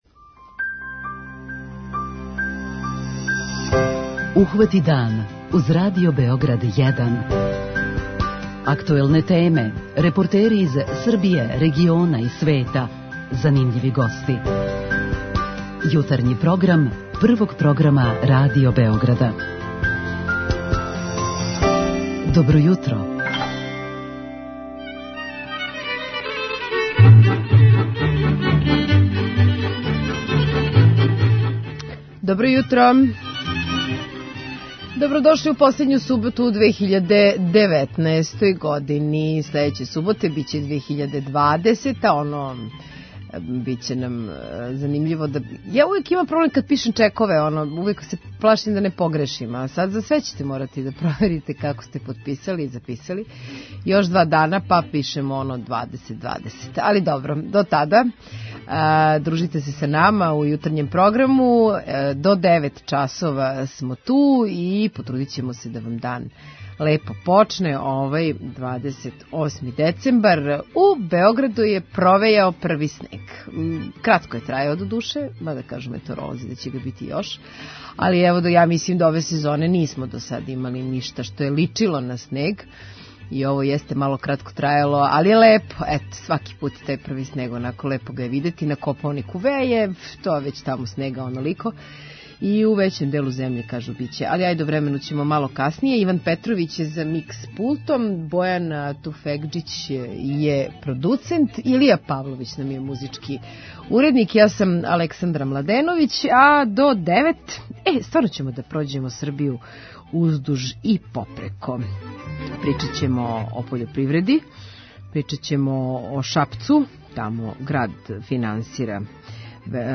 Овог суботњег јутра, последњег викенда у 2019. години, прошетаћемо се по многим крајевима наше земље и од дописника ћемо чути које су најактуелније теме.